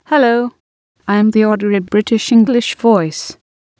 AT&T Natural Voices™ is the leading software solution for generating extremely natural-sounding voices.
They are almost indistinguishable from a real human speaker.
Audrey British English MP3